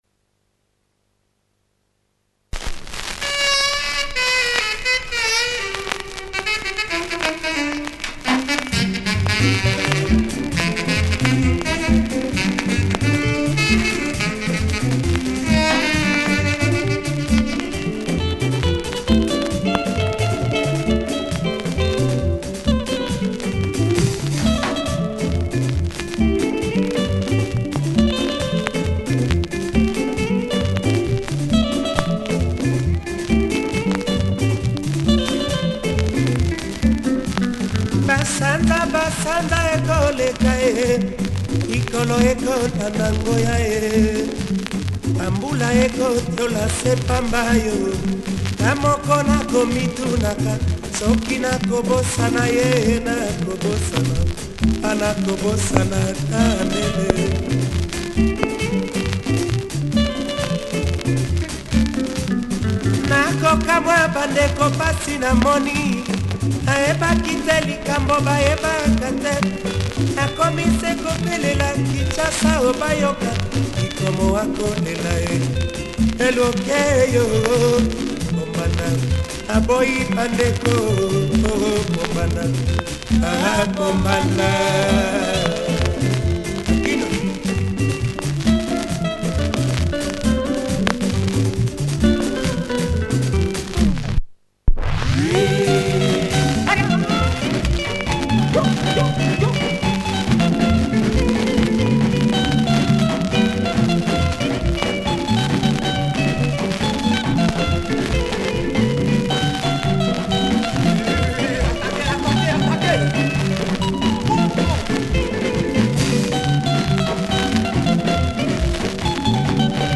Proper uplifting  Lingala
Bass heavy breakdown.